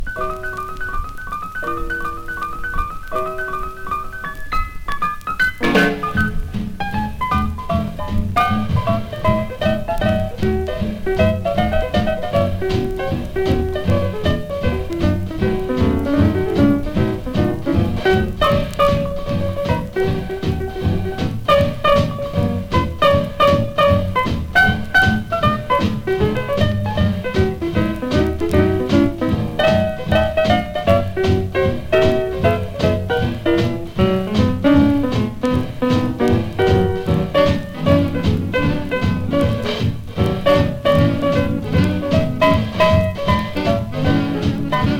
Jazz, Swing, Boogie Woogie　USA　12inchレコード　33rpm　Mono